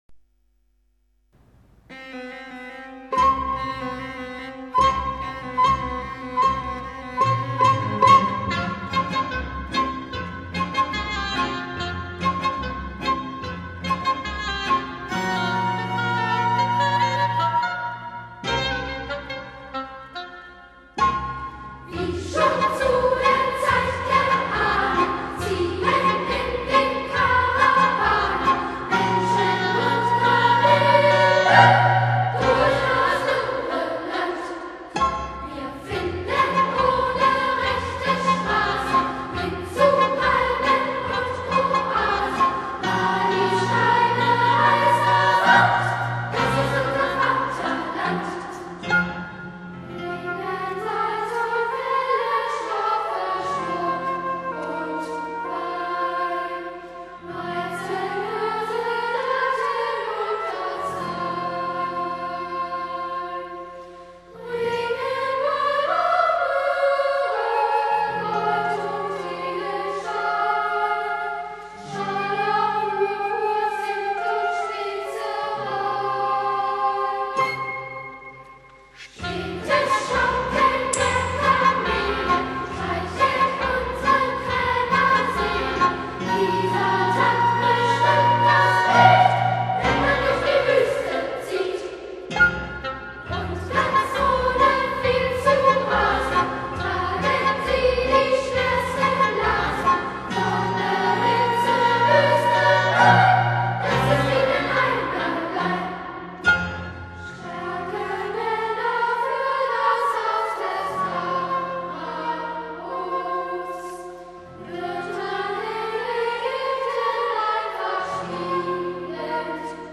Der Sprachschatz - kindgerecht.
Instrumente: Flöte, Violine, Klarinette, Klavier